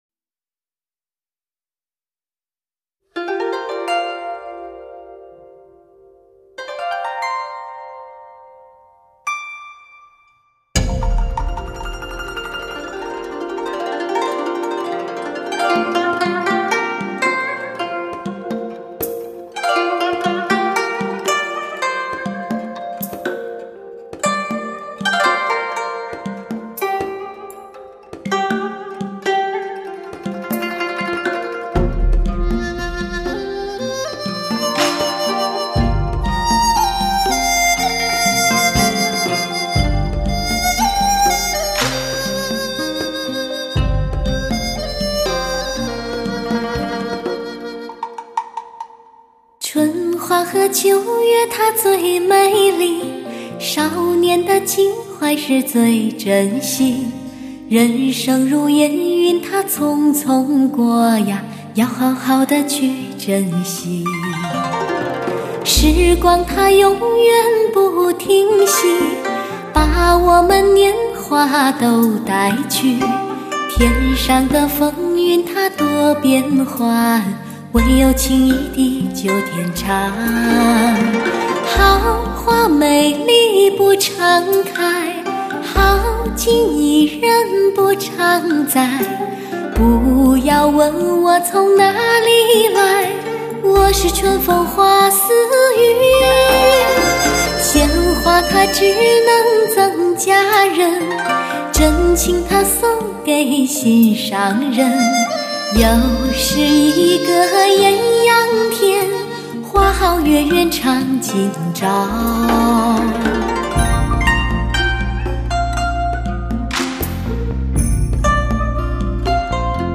贺岁歌曲，不同于港台。
喜欢这种民歌小调的韵味！